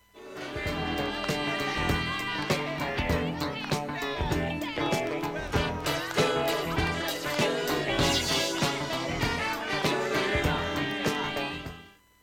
盤面きれいで音質良好全曲試聴済み。
A-2序盤に聴き取れないプツが4回出ます。
現物の試聴（上記録音時間録音時間）できます。音質目安にどうぞ
ほかわずかな単発のかすかなプツが８箇所
人気モダン・ソウル・ダンサー